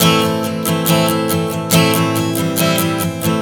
Strum 140 Am 02.wav